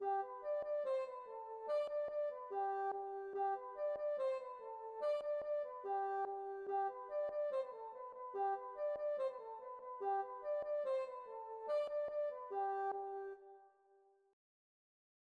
Gavotte